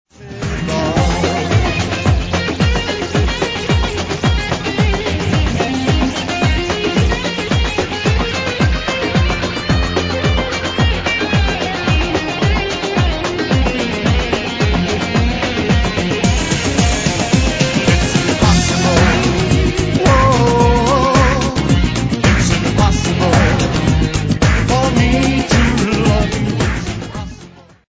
heavy gitarski intro - bez klavijatura!